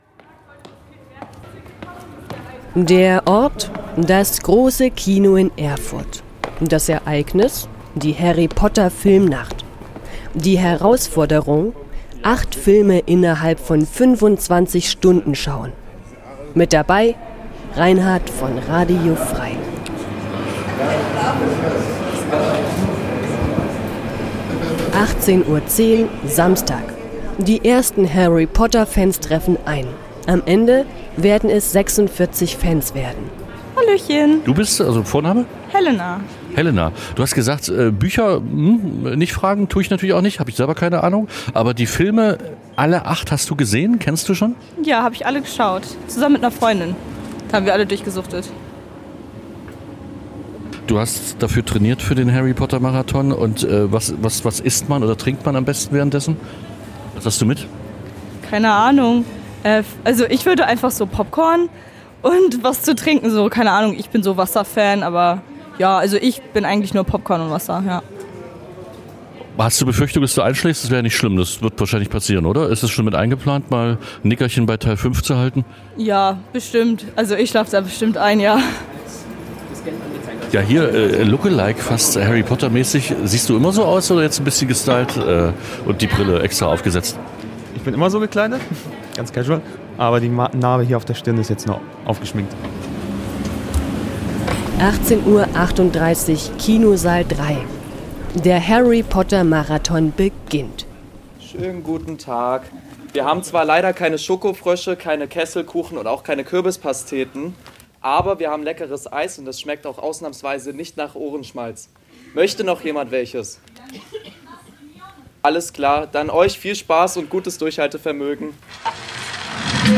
46 Harry-Potter- Fans haben sich Mitte September im Erfurter Cinestar die Nacht um die Ohren geschlagen, teils verkleidet als Bellatrix Lestrange oder Sirius Black. Der Grund: Alle 8 Harry-Potter-Verfilmungen wurden an einem Tag am Stück gezeigt.